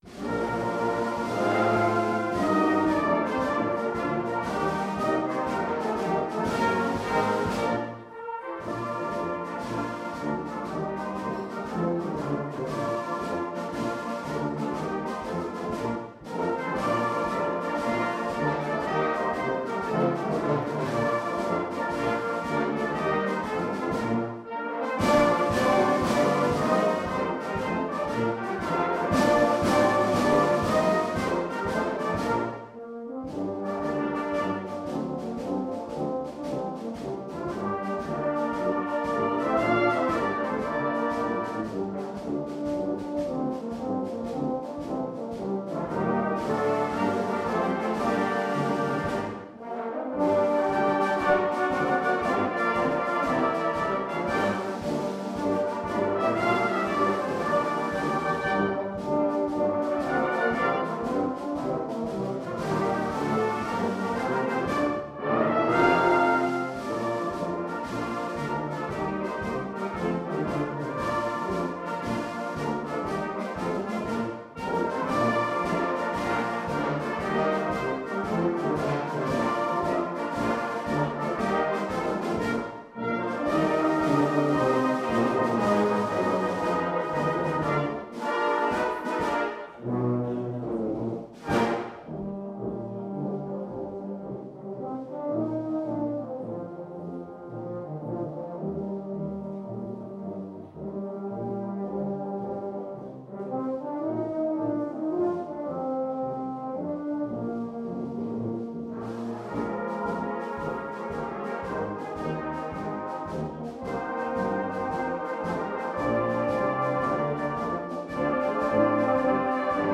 The following pieces were recorded at our concert at Foxearth Church on 19th September 2015
Barnard Castle by Goff Richards is one of the band's favourite marches, and we often use it to open our concerts.